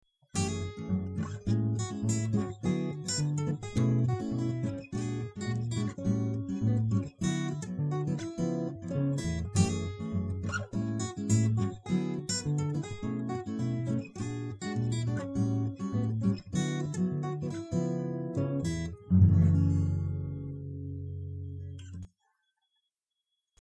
Guitar arrangements